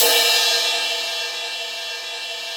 RIDE09.wav